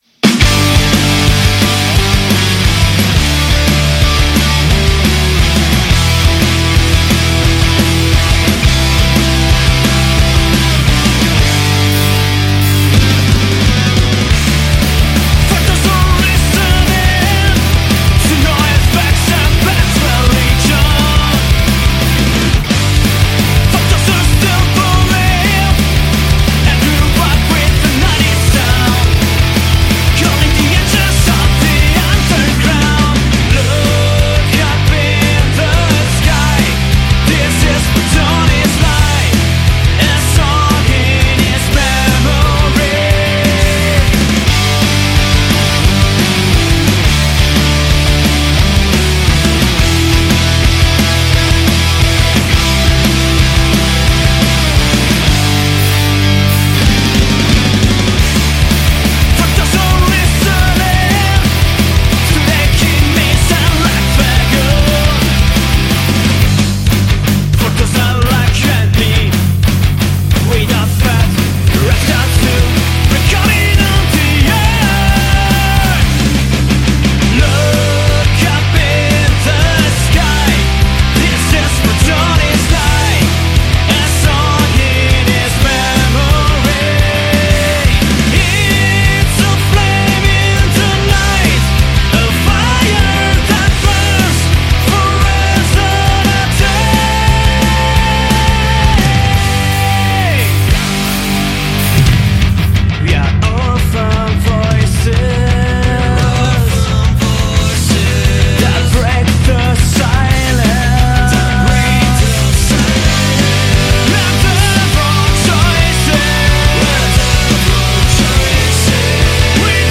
intervista-agli-splintera-rocktrotter-11-4-22.mp3